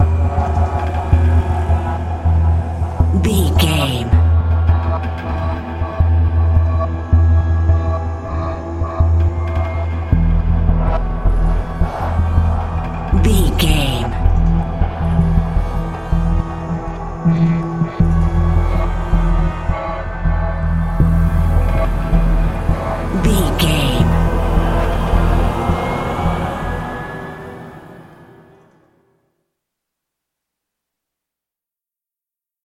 Aeolian/Minor
E♭
Slow
electric piano
synthesiser
tension
ominous
suspense
haunting
creepy